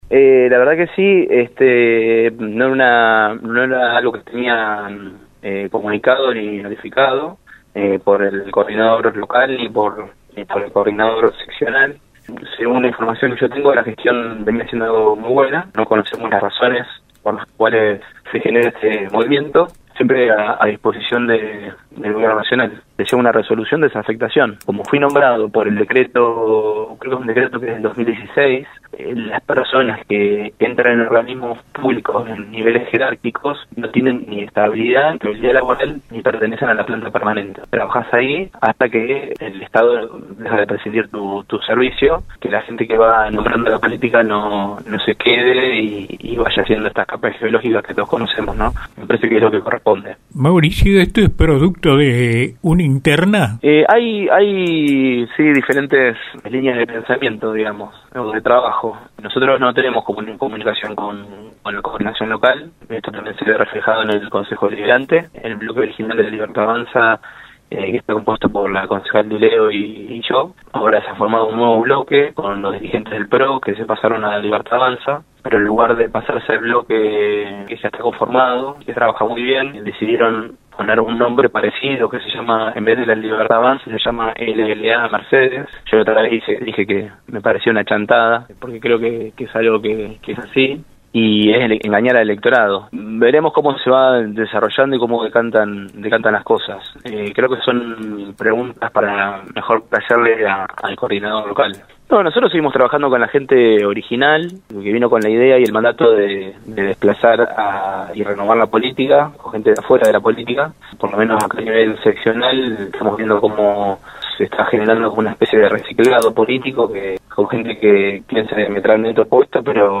MAURICIO POLLACHI EN RADIO UNIVERSO